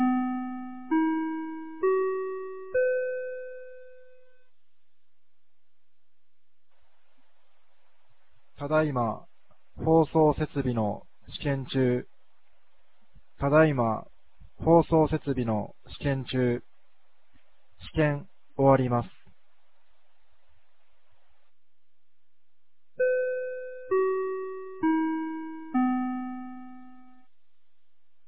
2024年08月10日 16時03分に、由良町から全地区へ放送がありました。